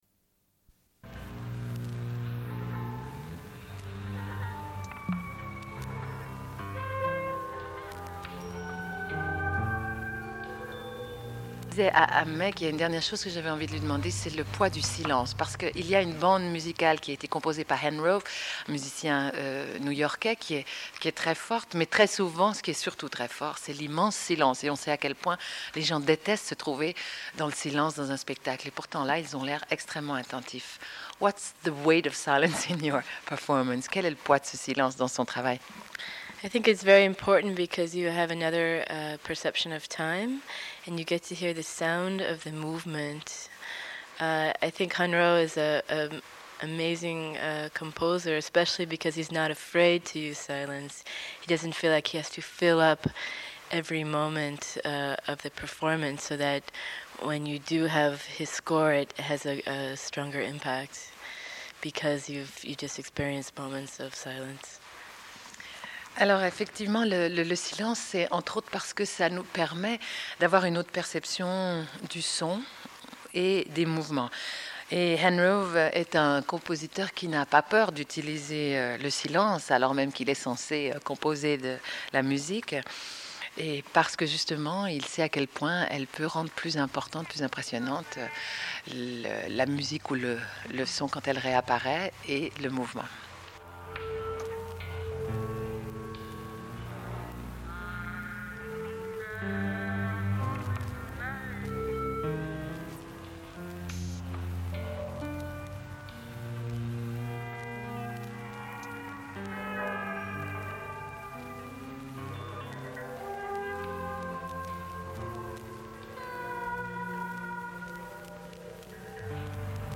Une cassette audio, face B29:05
Sommaire de l'émission : sur la danseur américaine Meg Stuart au sujet de son spectacle « No Longer Ready Made » présenté à la salle Patiño à Genève. Diffusion d'un entretien. Puis sur la poésie populaire de femmes pachtounes en Afghanistan, avec le livre Le suicide et le chant, textes recueillis par Sayd Bahodine Majrouh.